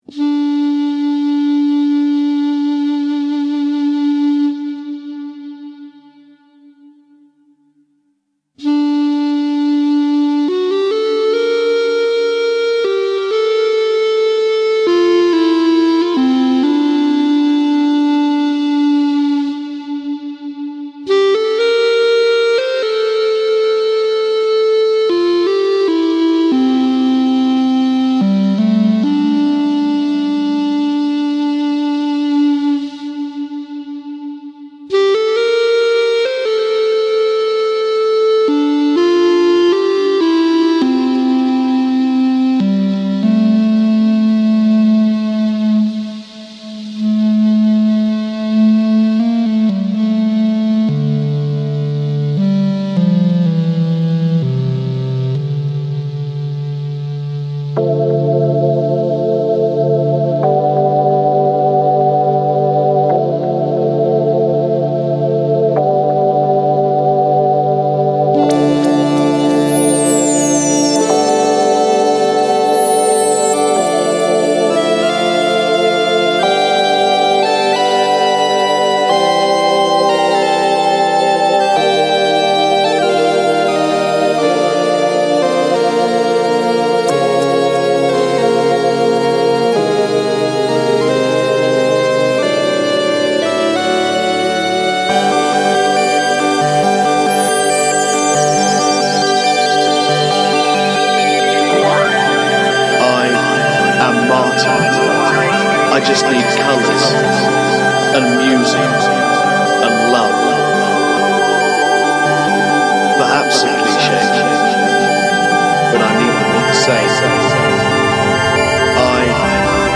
The final version included a rather strange love letter from Stephen Hawking to Tessa Sanderson, but in the original it was agreed (for some reason) that 'Celtic trance' was the way to go.
which includes a plinky-plonk version of the ...inexplicable... theme